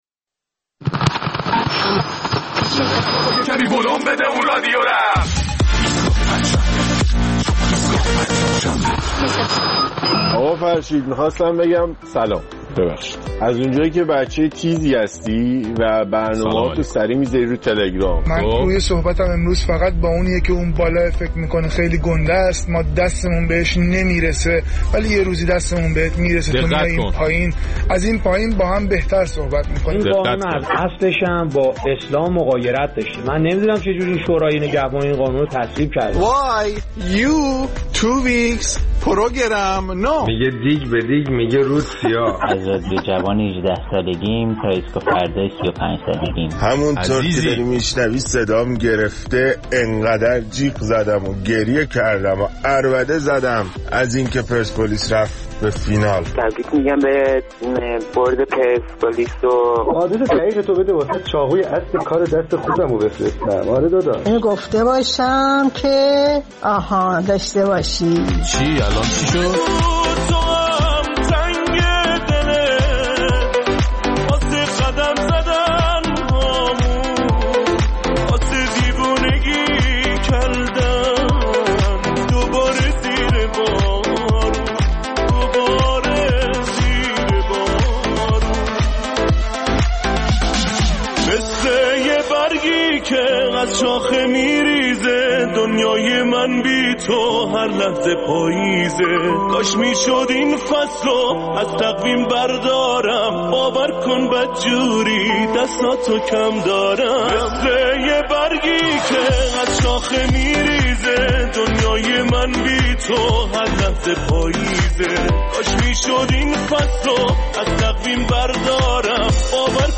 در این برنامه ادامه نظرات شما را در مورد اجرایی شدن قانون منع به کارگیری بازنشستگان در کشور و اثربخشی آن می‌شنویم.